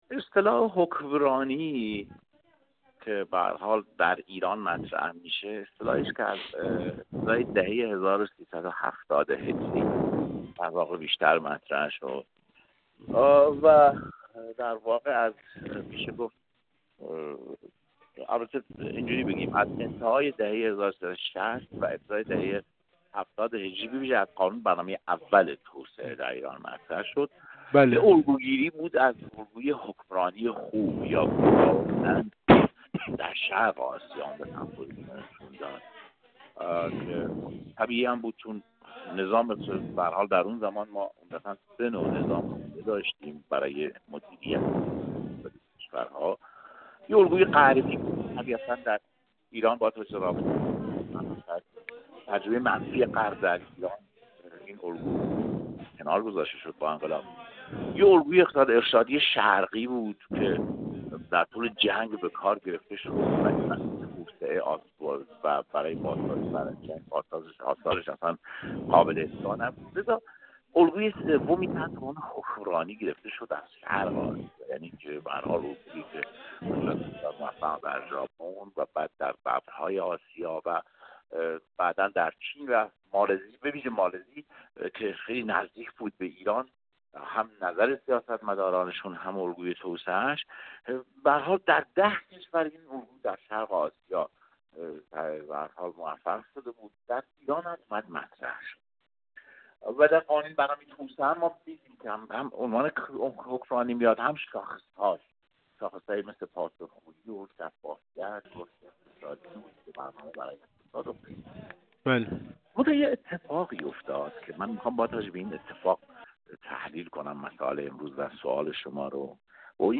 عضو هیئت علمی دانشگاه علامه طباطبایی با بیان اینکه هر جریان سیاسی در کشور که به مسئولیت رسیده، الگوی حکمرانی خوب را در خدمت قدرت قرار داده، نه توسعه، گفت: از این منظر مسئولانی که امروز از تغییر الگوی حکمرانی سخن می‌گویند نه با نگاه برنامه‌ای بلکه با نگاه تبلیغاتی آن را مطرح می‌کنند.